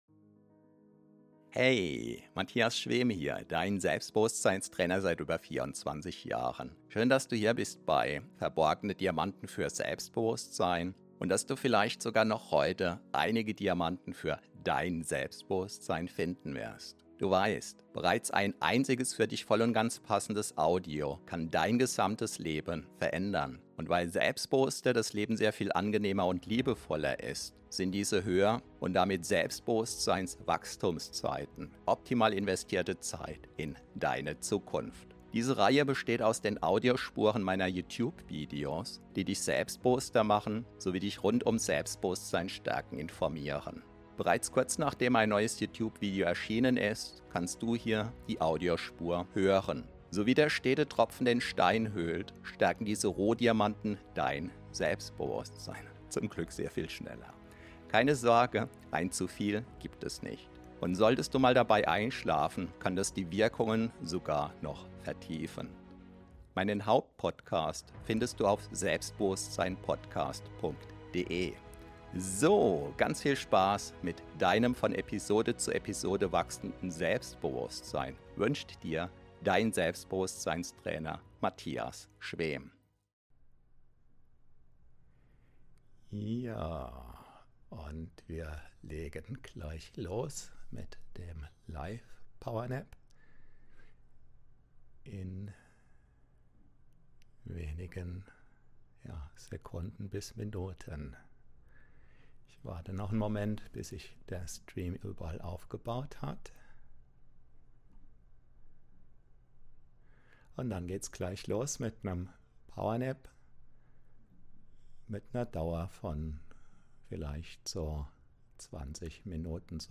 Sofort neue Energie dank Power-Nap 22 min LIVE! Info & Live-Power-Nap! ~ Verborgene Diamanten Podcast [Alles mit Selbstbewusstsein] Podcast